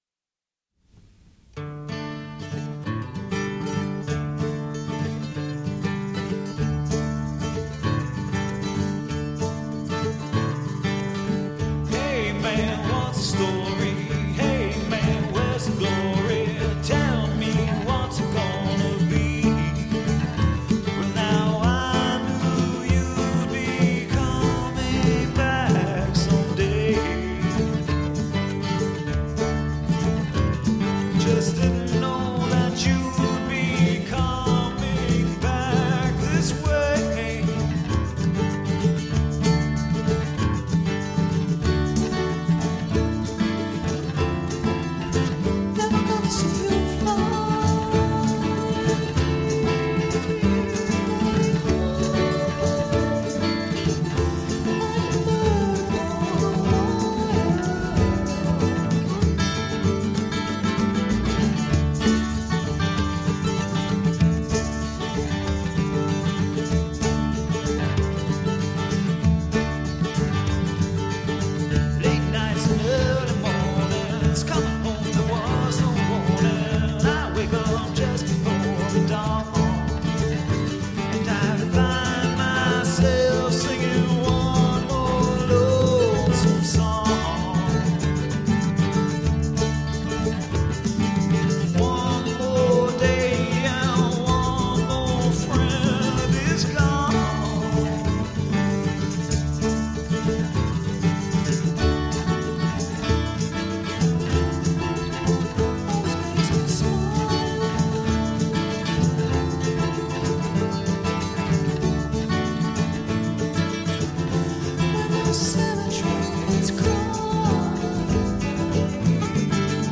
-banjo
Violin
guitar
harmonica
Bass
percussion